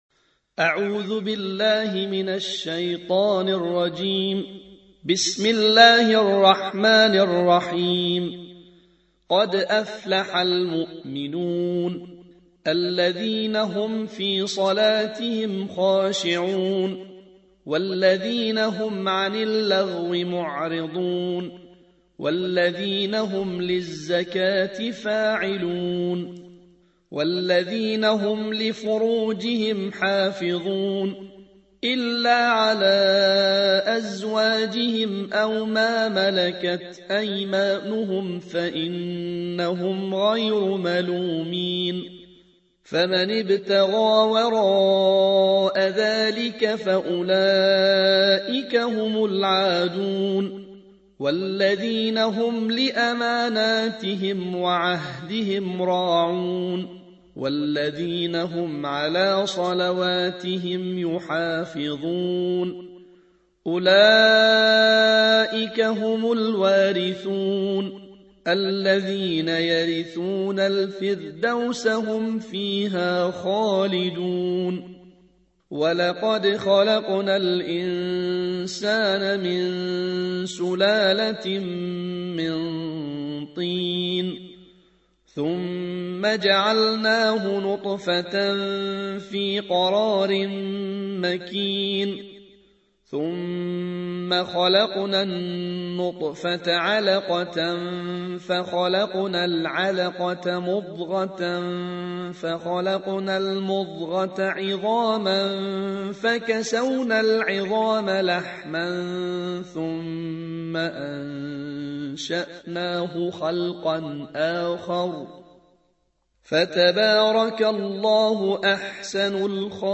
الجزء الثامن عشر / القارئ